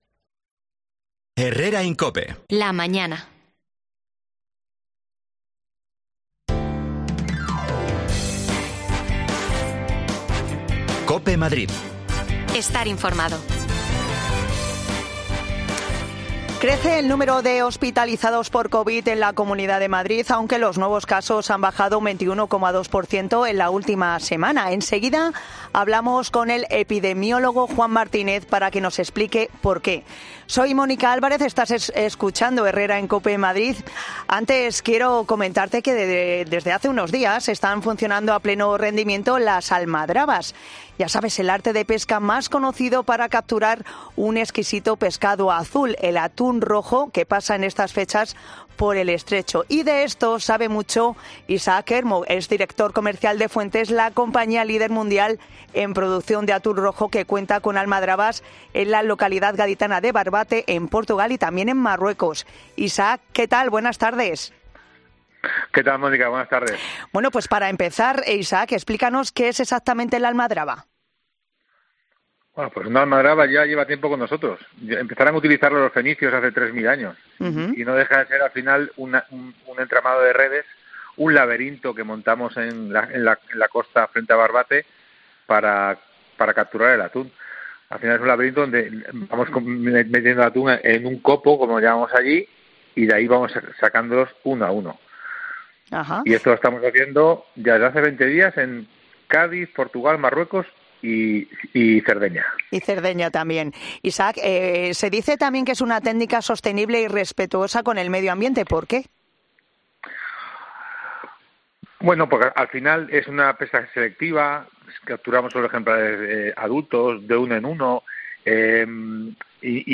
Hablamos con expertos para analizar esta situación
Las desconexiones locales de Madrid son espacios de 10 minutos de duración que se emiten en COPE , de lunes a viernes.